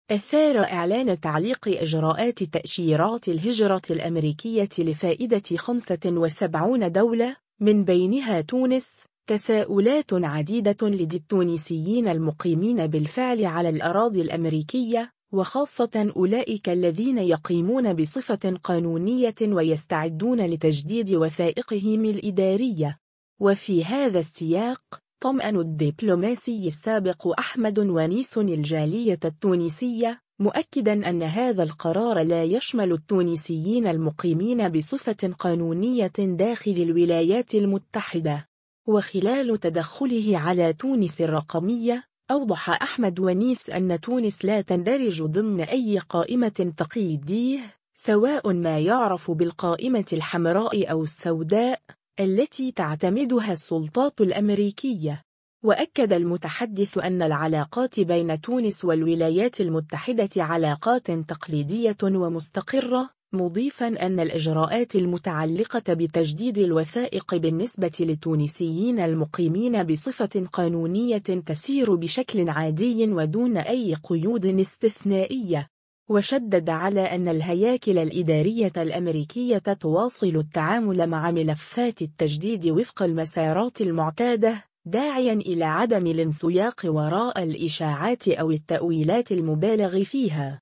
وخلال تدخله على تونس الرقمية، أوضح أحمد ونيس أن تونس لا تندرج ضمن أي قائمة تقييدية، سواء ما يُعرف بالقائمة الحمراء أو السوداء، التي تعتمدها السلطات الأمريكية.